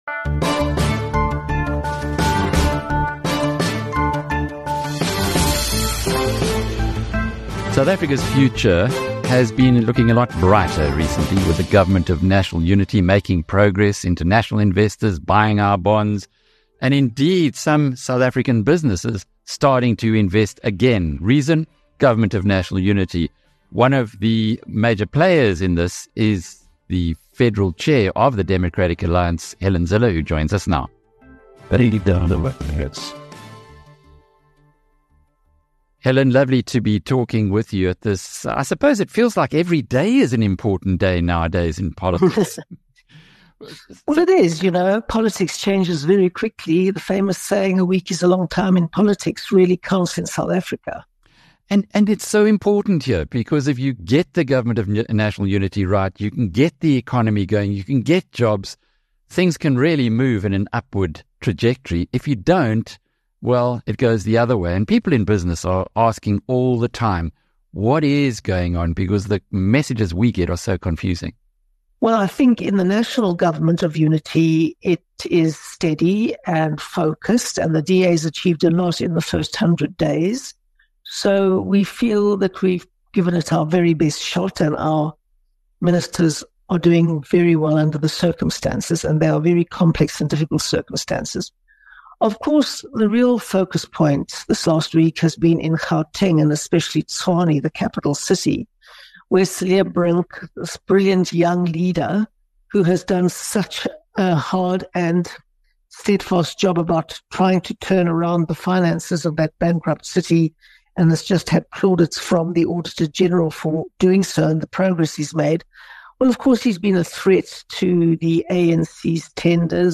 In a candid interview with BizNews, DA Federal Chairperson Helen Zille discusses the government's progress, challenges, and the role of the DA in the coalition government. She delves into Herman Mashaba's political manoeuvrings, the EFF-DA rivalry, and the pressing issues in Gauteng and Tshwane. Zille highlights the DA’s focus on constitutionalism, economic growth, and the party's successful young leadership pipeline.